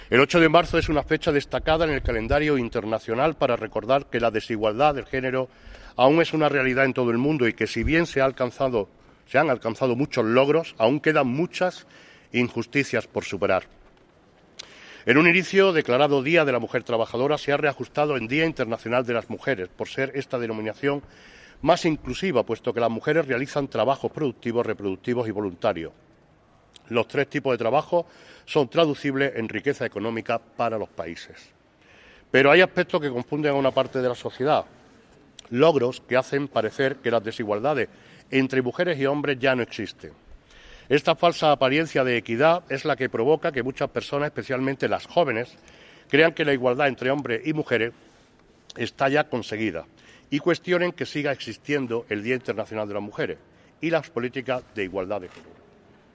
El patio del Ayuntamiento de Antequera acogía en el mediodía de hoy martes 8 de marzo la lectura simbólica por parte de todos los grupos políticos municipales del manifiesto conjunto aprobado en el Pleno ordinario del pasado mes de febrero. El alcalde Manolo Barón presidía el acto.
Cortes de voz